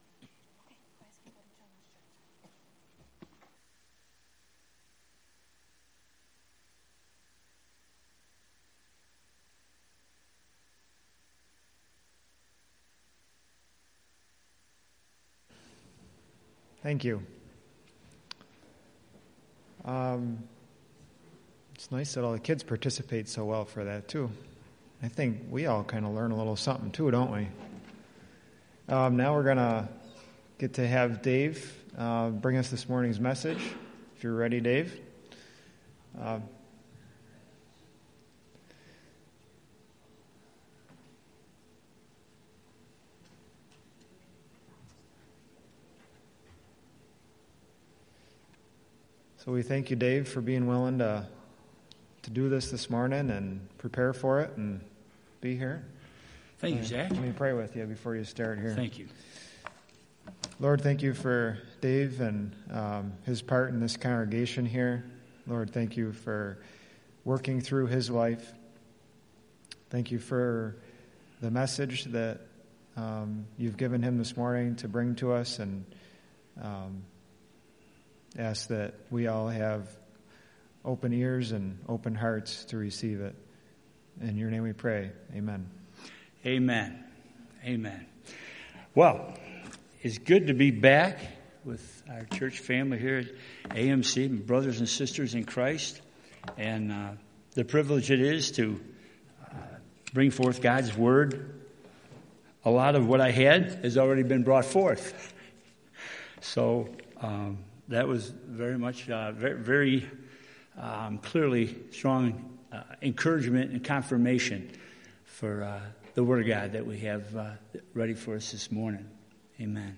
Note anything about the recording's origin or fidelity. Passage: Luke 1:1-4 Service Type: Sunday Morning « Guatemala 2018